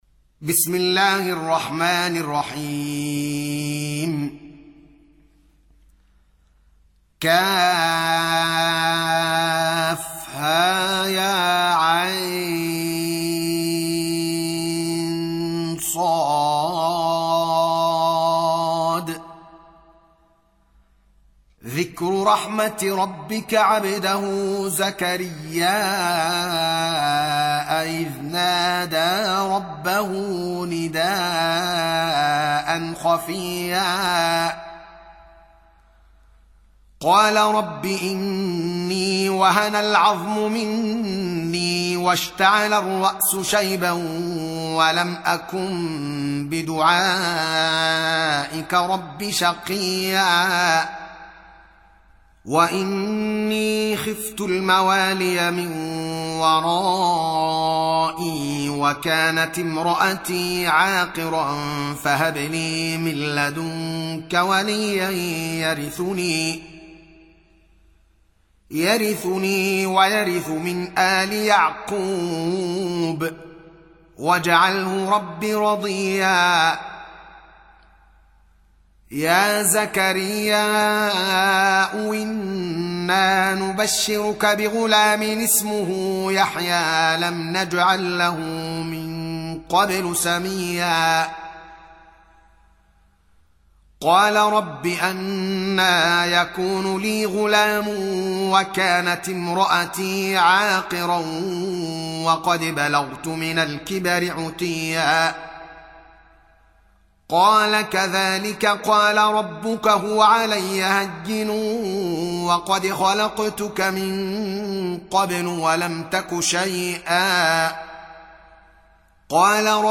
Surah Repeating تكرار السورة Download Surah حمّل السورة Reciting Murattalah Audio for 19. Surah Maryam سورة مريم N.B *Surah Includes Al-Basmalah Reciters Sequents تتابع التلاوات Reciters Repeats تكرار التلاوات